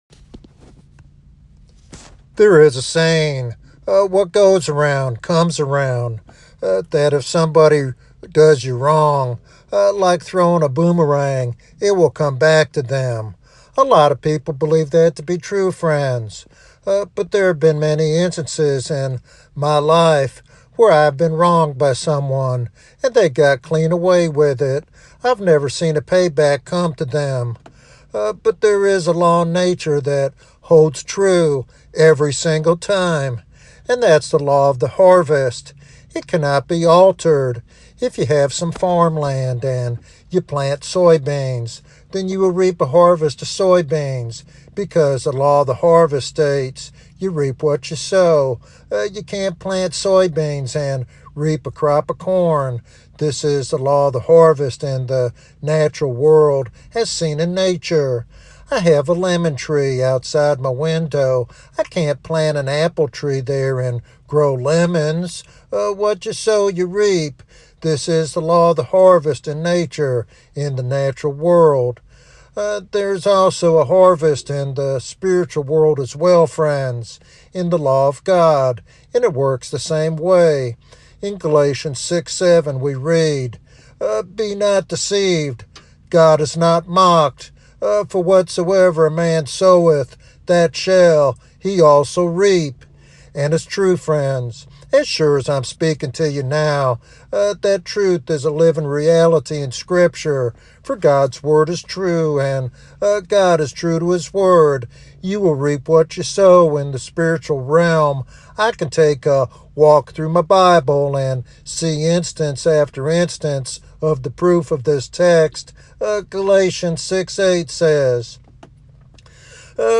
This sermon challenges listeners to recognize the seriousness of their choices and to remain close to Christ to avoid the destructive whirlwind of sin.